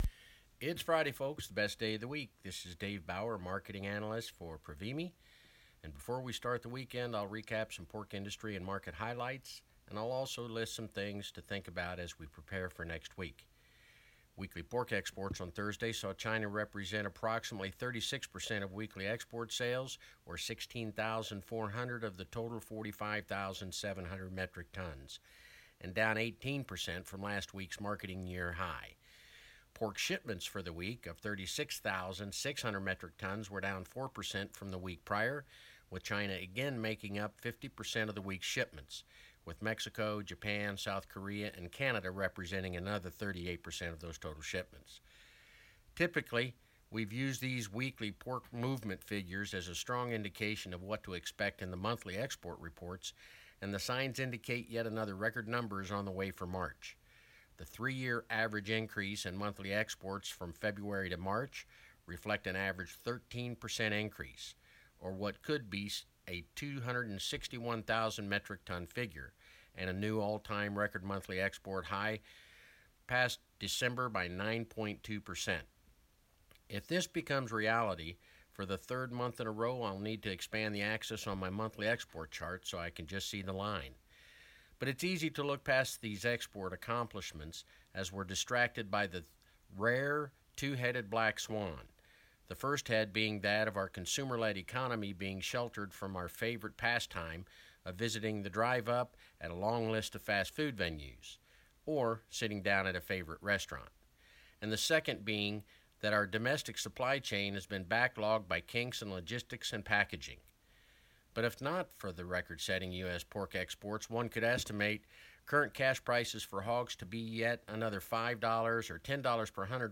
FEEDSTUFFS PRECISION PORK Market Report - April 17